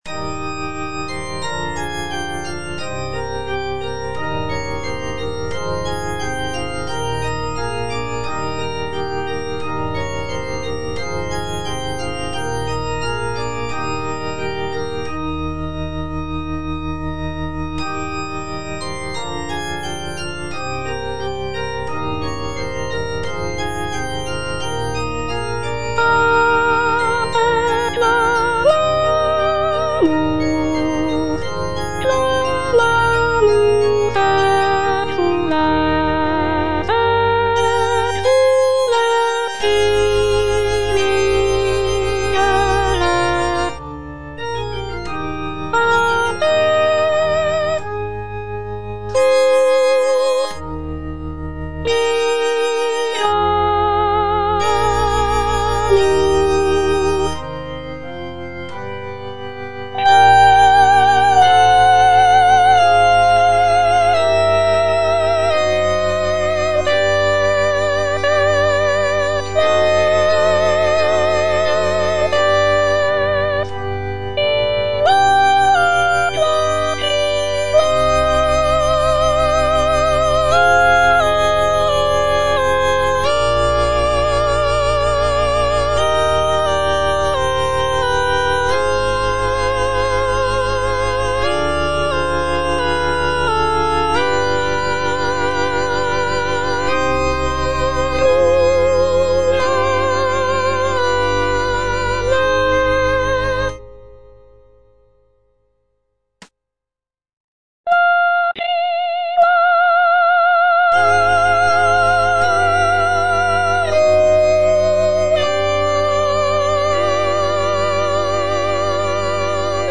G.B. PERGOLESI - SALVE REGINA IN C MINOR Ad te clamamus - Soprano (Voice with metronome) Ads stop: auto-stop
"Salve Regina in C minor" is a sacred choral work composed by Giovanni Battista Pergolesi in the early 18th century. It is a setting of the traditional Marian antiphon "Salve Regina" and is known for its poignant and expressive melodies.